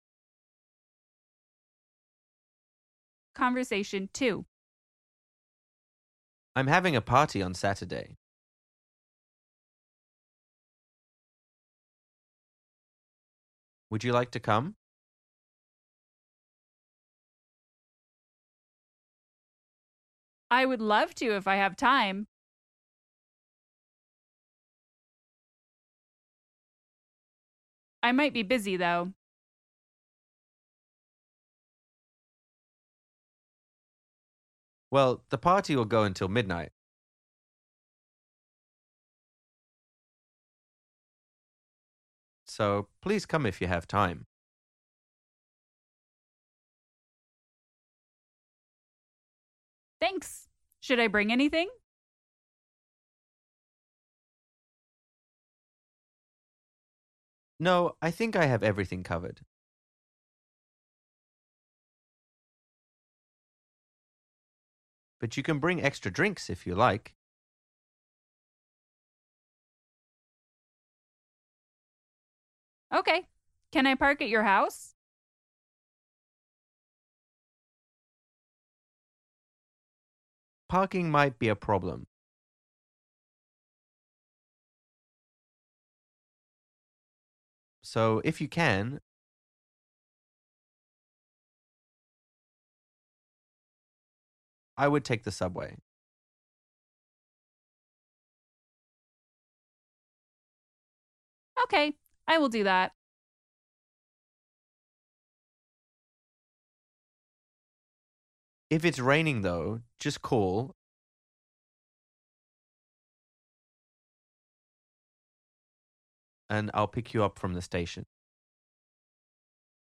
Man: I’m having a party of Saturday. Would you like to come?